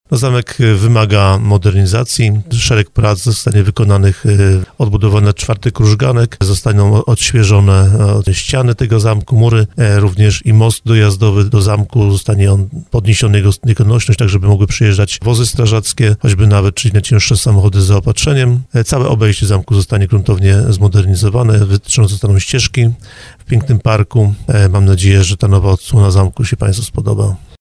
Mówił o tym w audycji Słowo za Słowo wiceprzewodniczący Sejmiku Województwa Małopolskiego Wojciech Skruch.